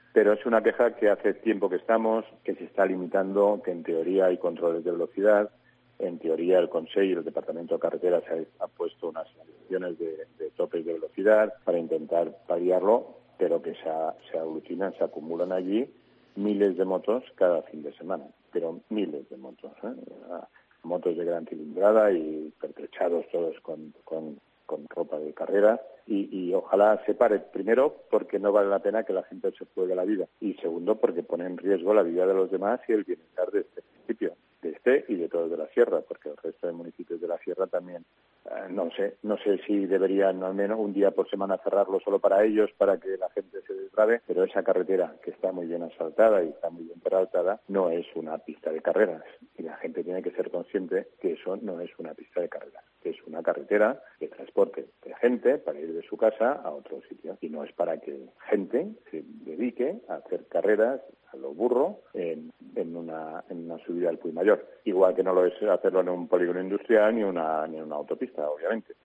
Carlos Simarro, alcalde de Sóller